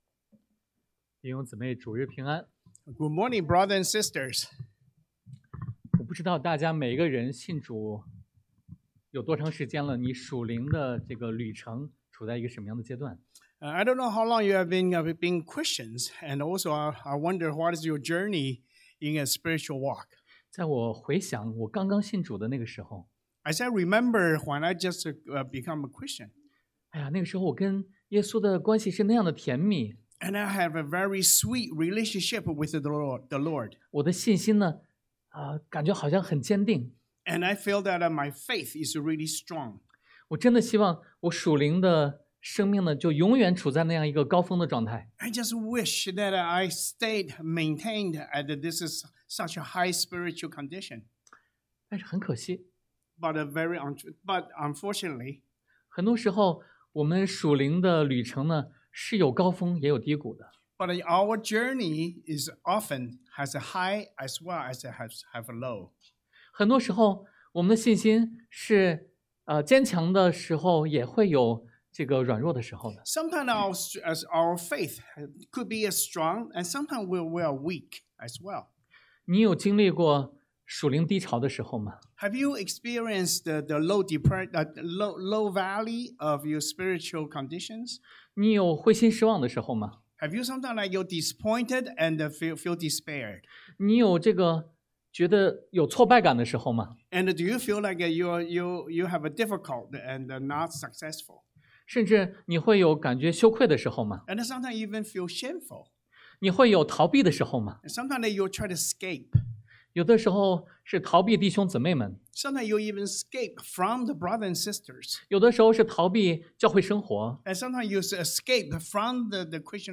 Passage: 约翰福音 John 21 Service Type: Sunday AM